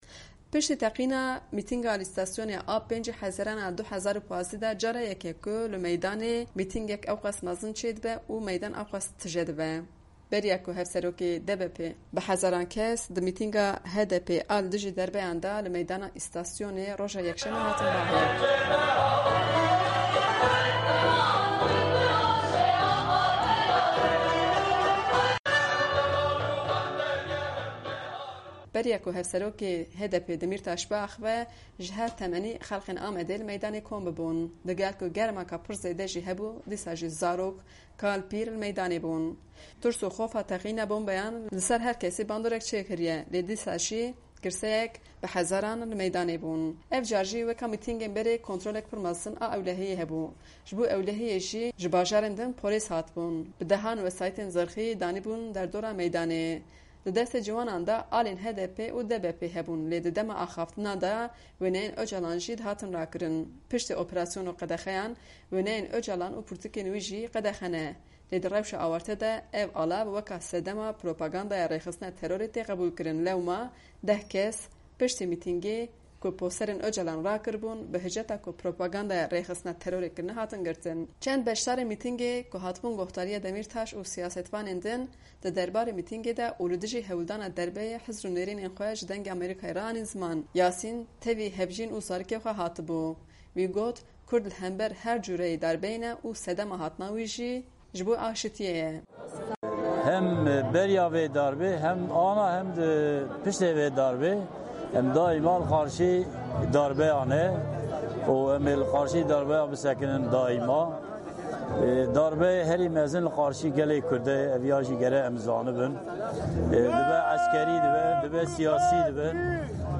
Mîtînga HDP li Amed(Diyarbekir)ê
Hun dikarin di fayla dengî de nêrînên beşdarên mîtingê gohdar bikin.